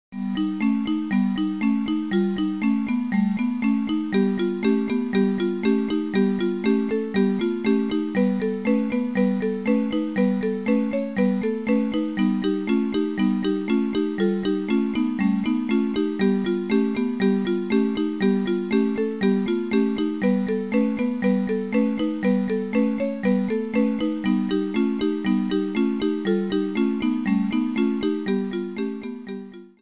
Clipped and applied fade-out with Audacity.